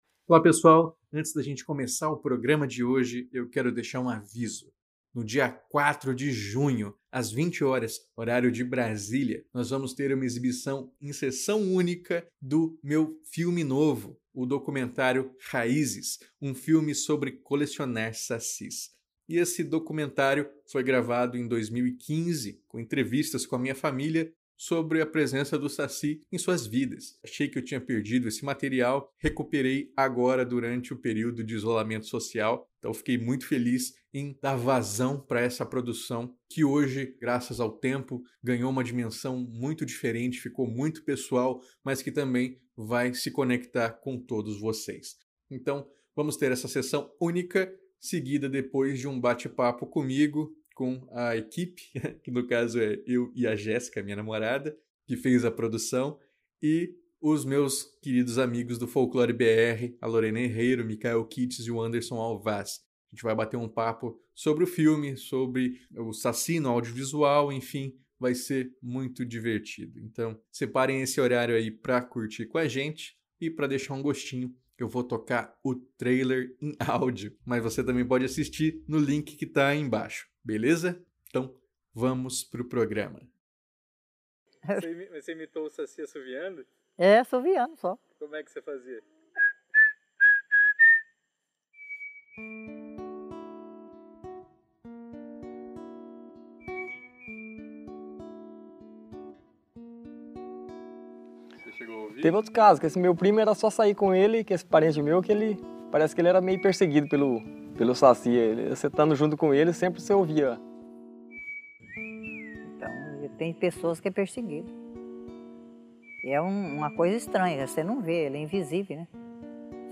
Podcast que entrevista a escritora
– Canto de abertura e encerramento do povo Ashaninka .